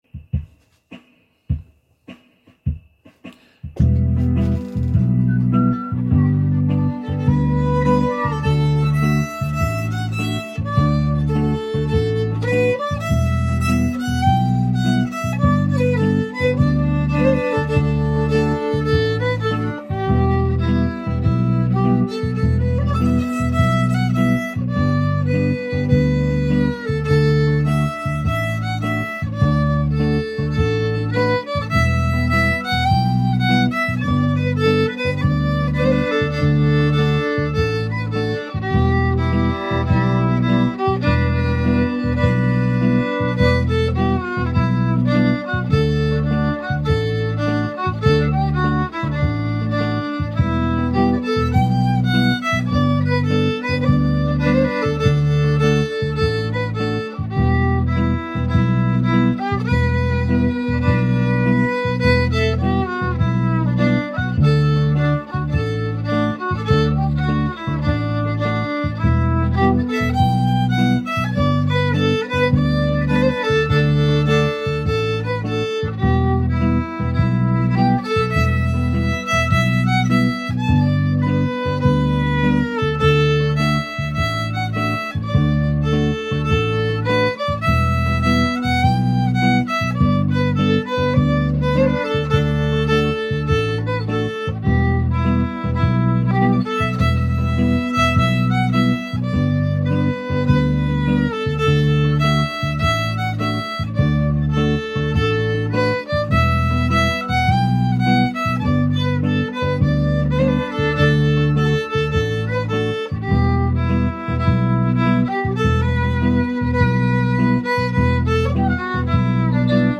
Newest 100 Songs fiddle songs which Fiddle Hangout members have uploaded to the website.